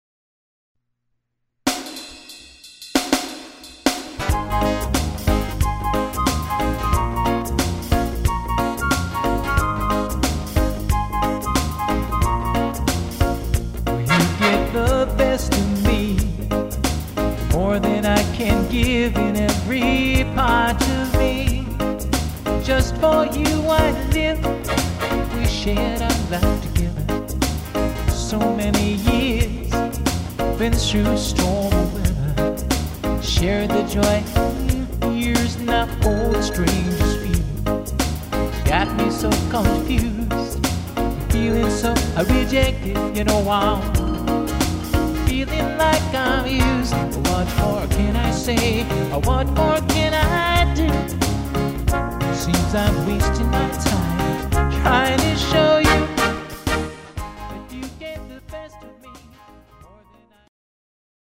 A bouncy tune